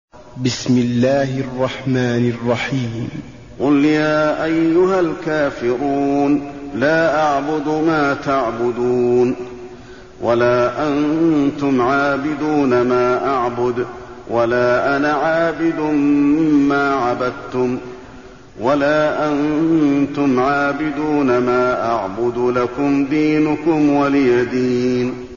المكان: المسجد النبوي الكافرون